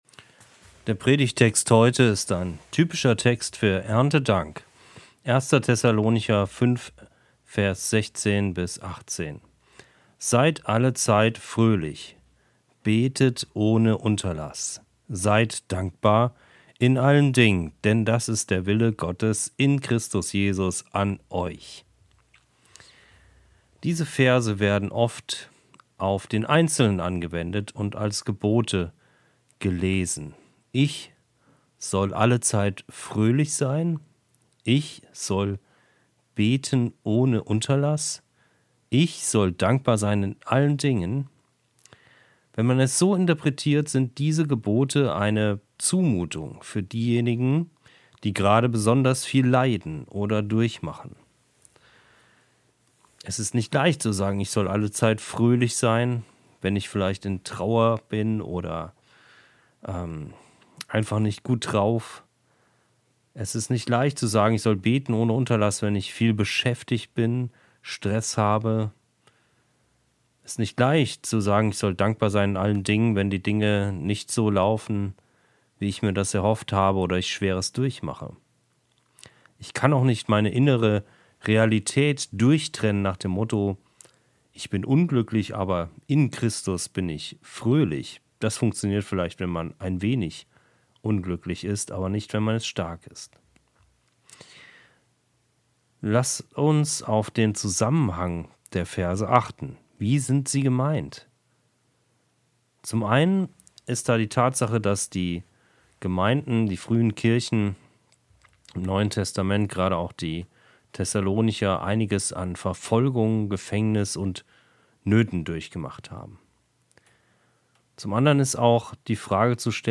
Dankbarkeit ist nicht nur etwas, das wir als Einzelne, sondern auch als Gemeinde einüben können. Wie sieht eine Gemeinde aus, in der Dankbarkeit normal ist? (Die Predigt wurde nachgesprochen).